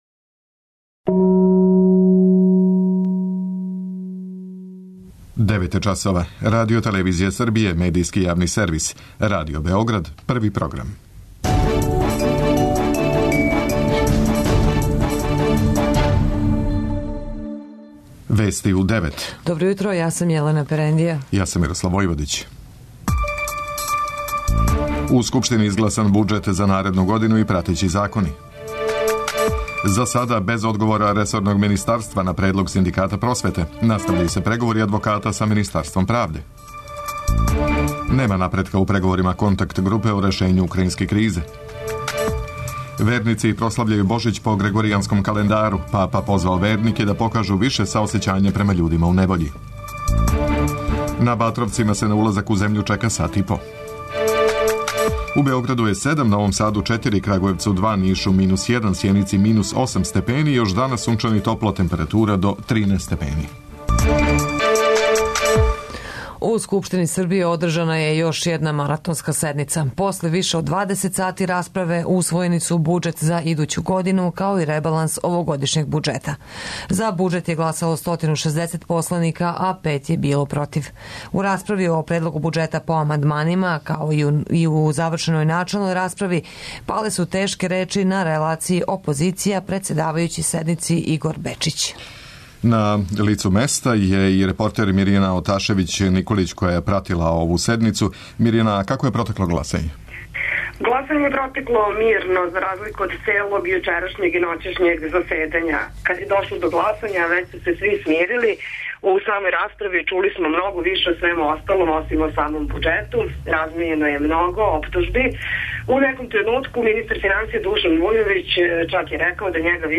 преузми : 10.90 MB Вести у 9 Autor: разни аутори Преглед најважнијиx информација из земље из света. [ детаљније ] Све епизоде серијала Радио Београд 1 Национални савет Рома у посети Јагодини Ангелина, светска - а наша!